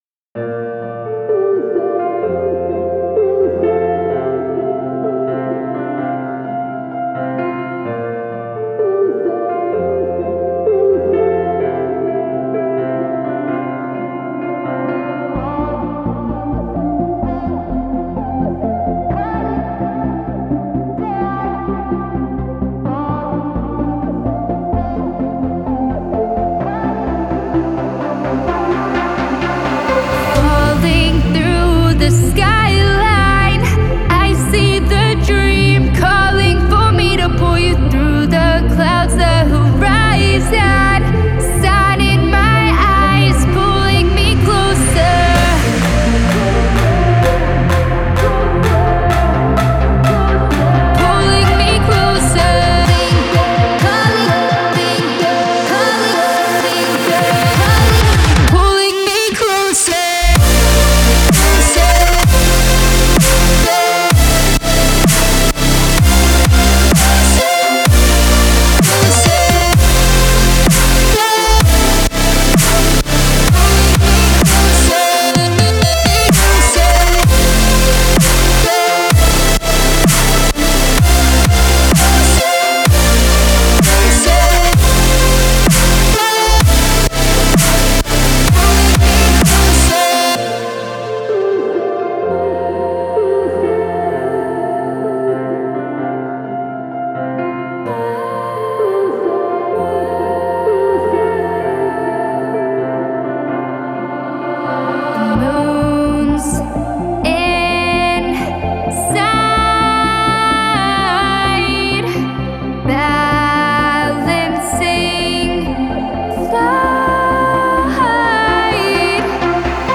это энергичная электронная композиция в жанре EDM
вокалисткой